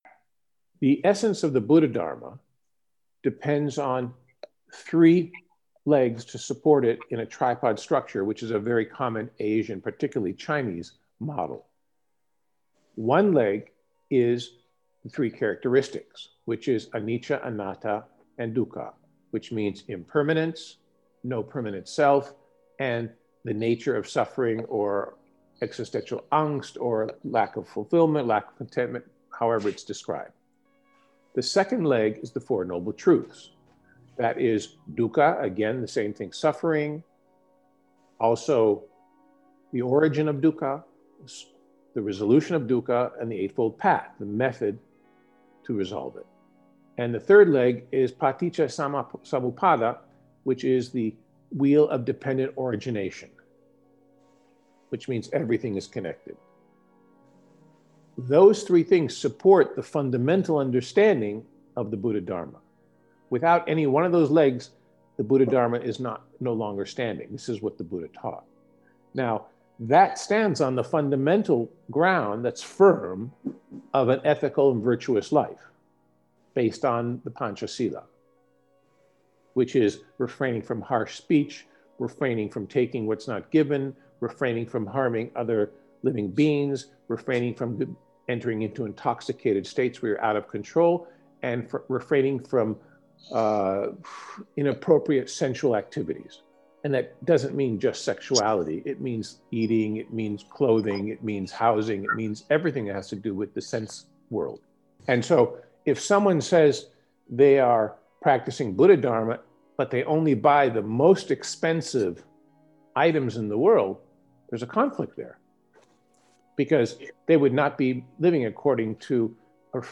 Path to Wisdom :: Conversation
Excerpt from Sunday Dec. 6, our discussion and analysis of the Heartwood Sutta and begin to consider the 4 Jhanas-breathing, visualisation and the purpose of using philosophy in daily life.